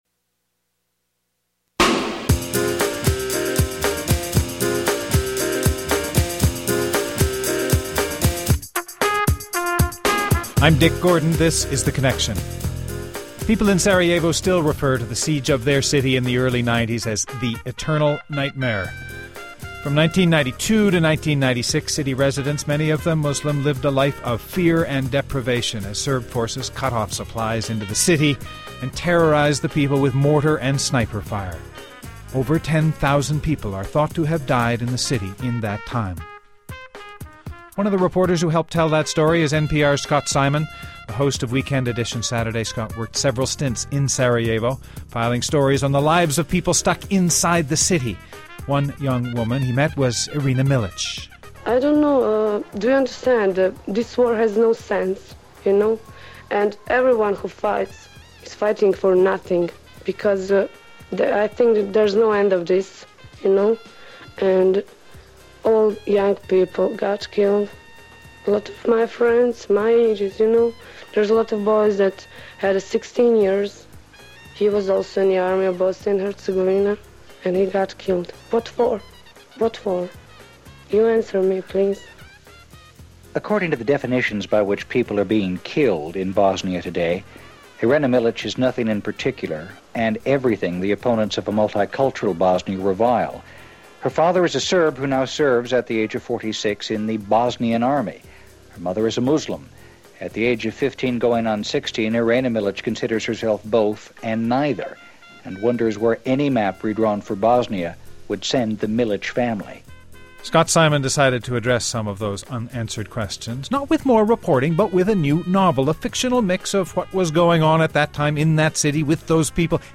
Guests: Scott Simon, author of “Pretty Birds,” and host of NPR’s Weekend Edition Saturday